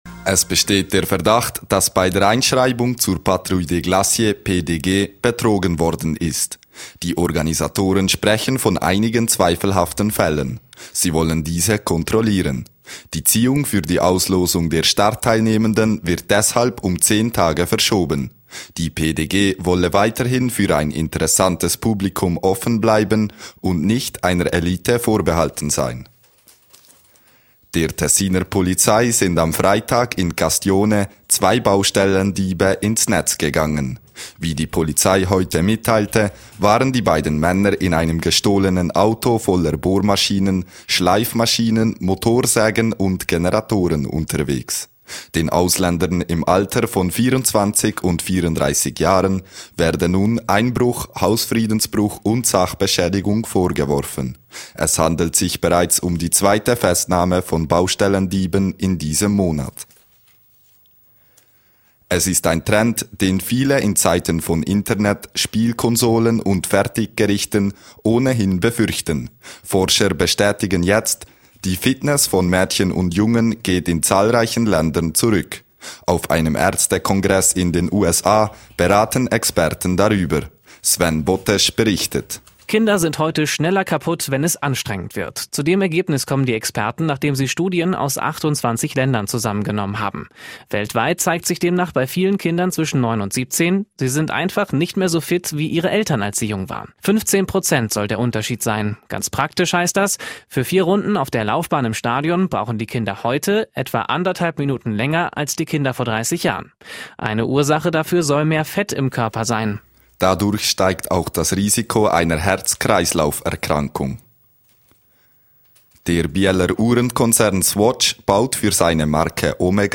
17:00 Uhr Nachrichten (2.92MB)